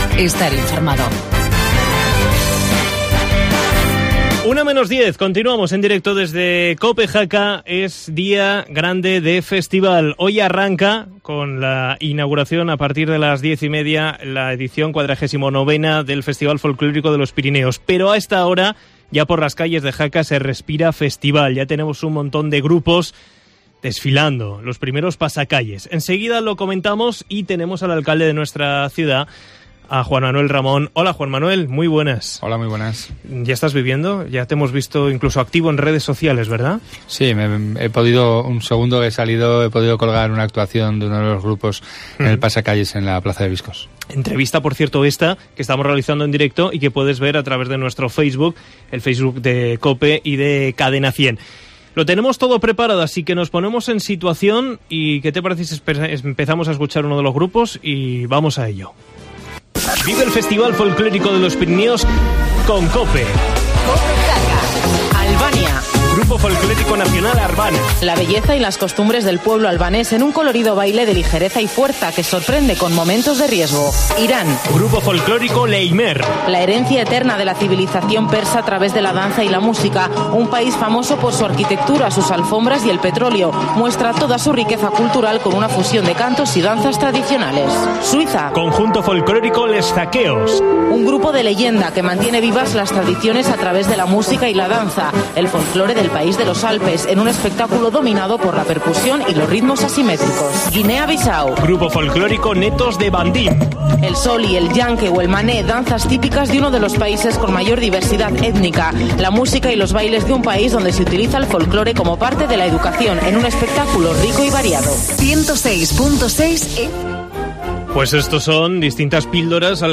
El alcalde de Jaca visita los estudios de Cope